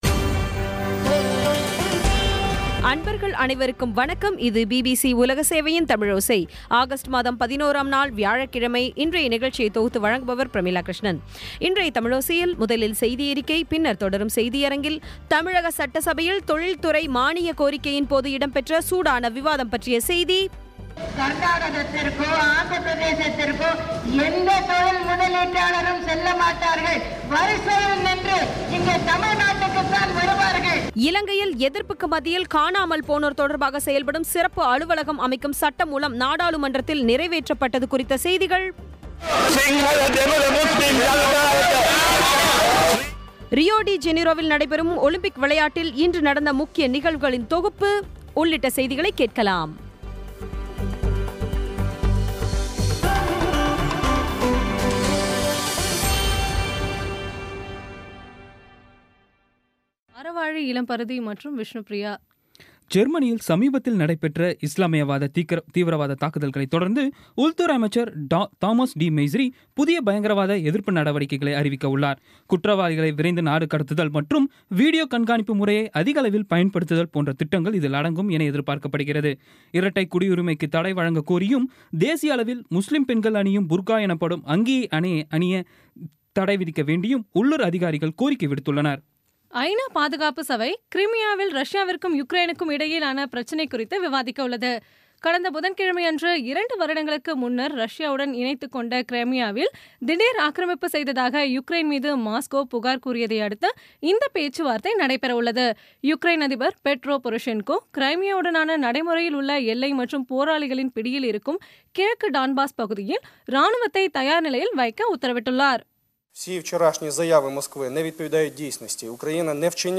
இன்றைய தமிழோசையில், முதலில் செய்தியறிக்கை பின்னர் தொடரும் செய்தியரங்கில்,